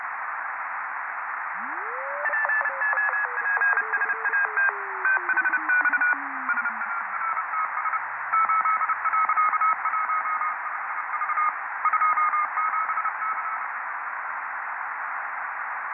188 Кб 20.09.2014 13:53 POPSAT-HIP-1 CW Beacon & Telemetry 2014-09-20 07:01UTC 50RS027